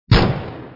pistolBig.mp3